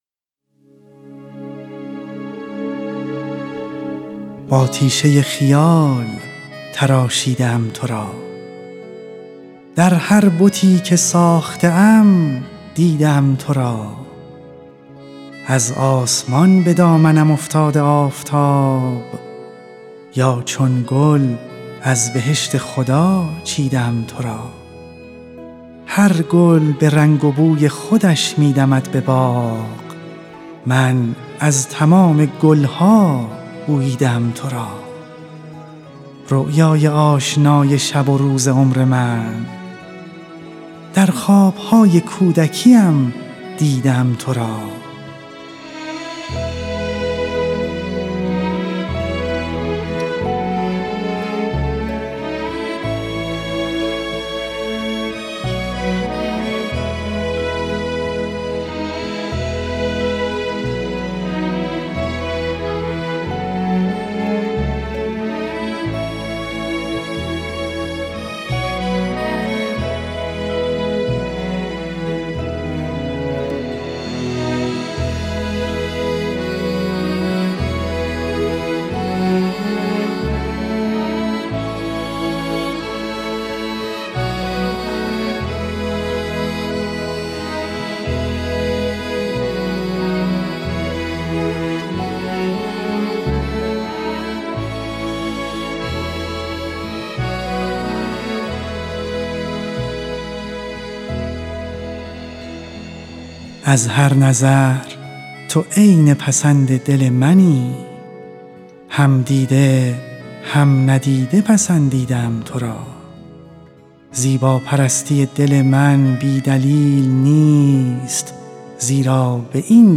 "رؤیای آشنا" یکی از سروده‌های قیصر امین‌پور است که در قالب پروژه‌ی «پرواز خیال» به صورت موسیقی - گفتار(دکلمه) اجرا شده است.
در این مجموعه برخی از سروده‌های مهم ادبیات ایران و جهان انتخاب و با همراهی موسیقی خوانده شده‌اند.